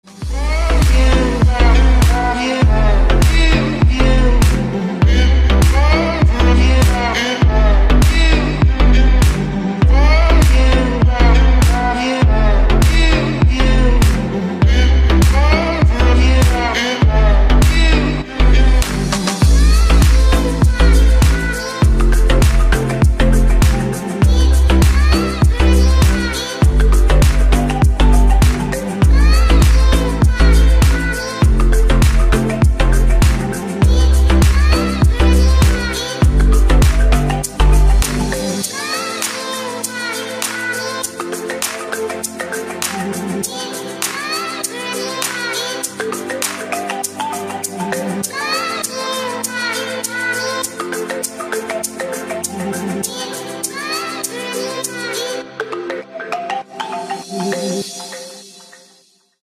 • Качество: 256, Stereo
deep house
Electronic
спокойные
tropical house
смешной голос